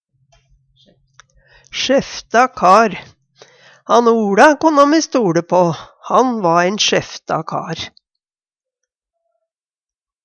sjefta kar - Numedalsmål (en-US)